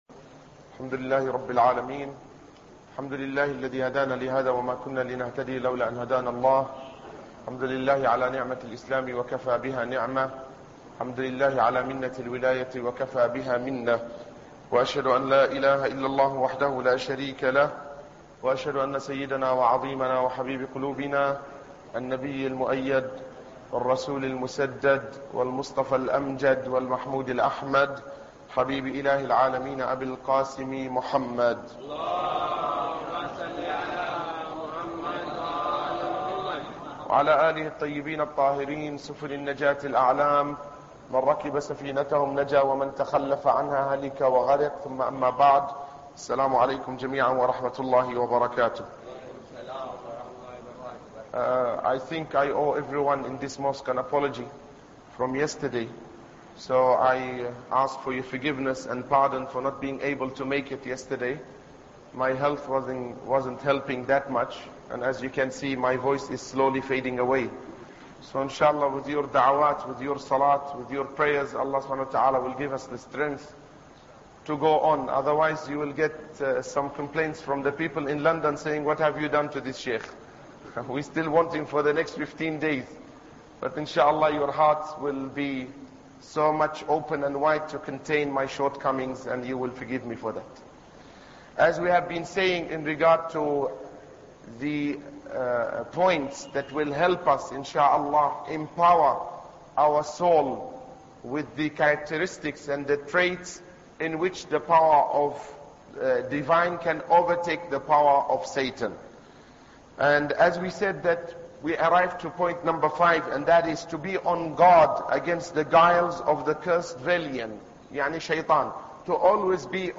Ramadan Lecture 7